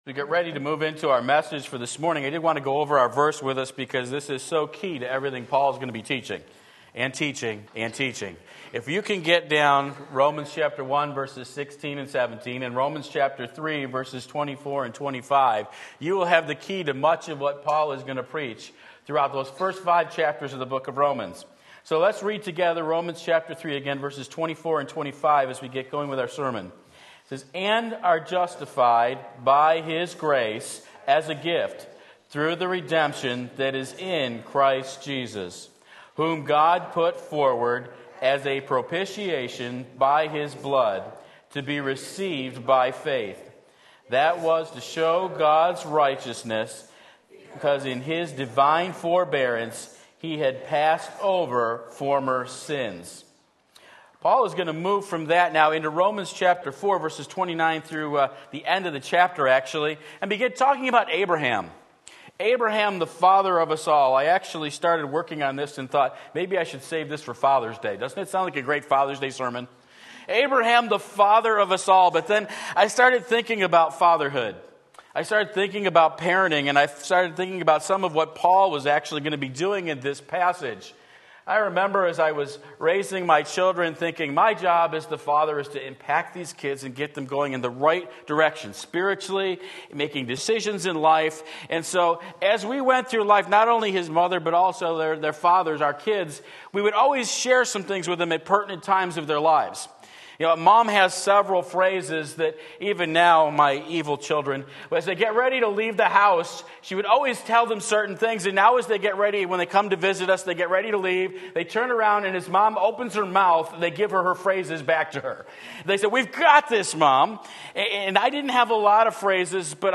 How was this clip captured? the Father of Us All Romans 4:9-13 Sunday Morning Service